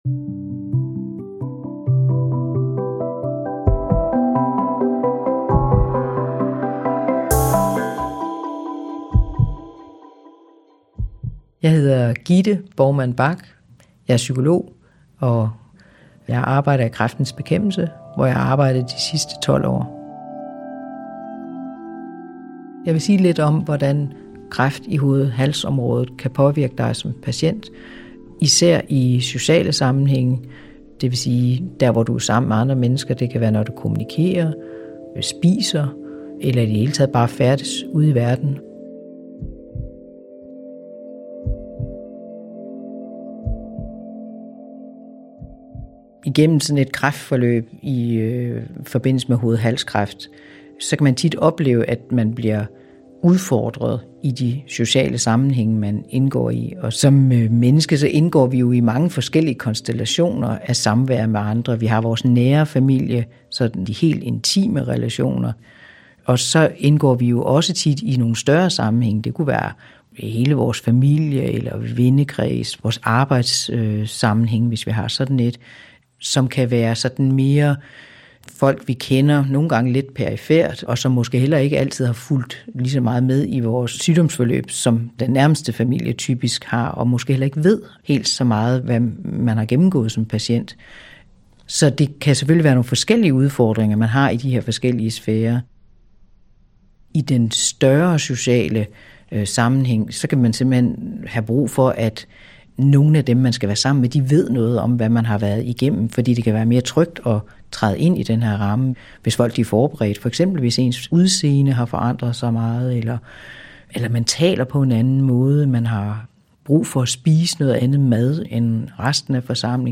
Lydfil: Psykologen fortæller